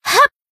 贡献 ） 分类:蔚蓝档案语音 协议:Copyright 您不可以覆盖此文件。
BA_V_Hina_Battle_Shout_2.ogg